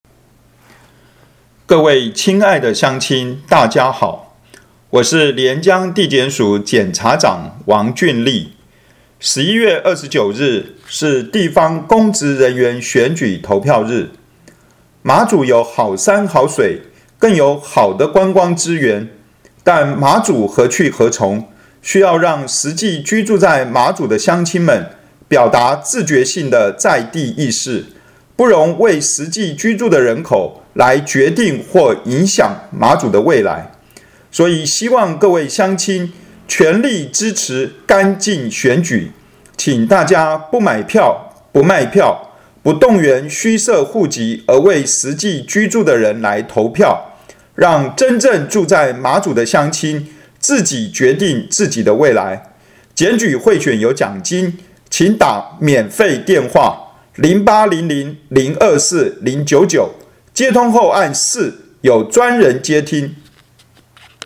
檢察長反賄選宣導廣播.mp3 (另開新視窗)